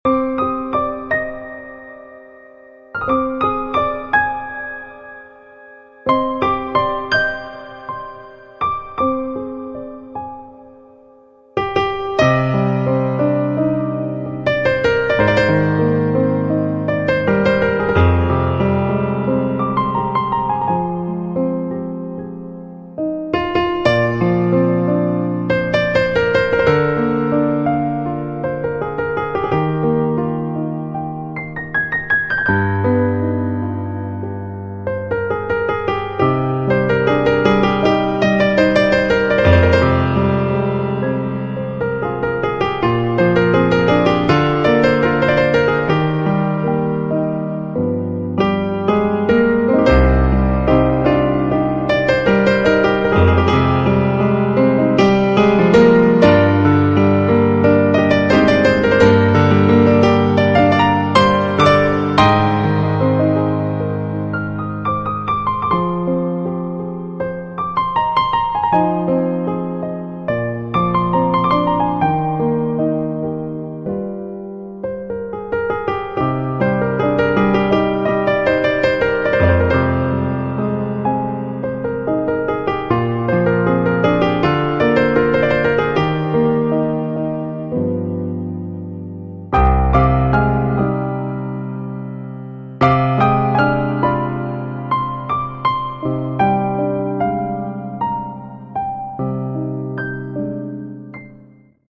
gstnaya_muzyka_bez_slov___3.mp3